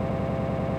To make a loop “smooth”, the start and end need to match up, like in this sample.
If you load this into Audacity and apply the “Repeat” effect, you should find that it plays without stuttering.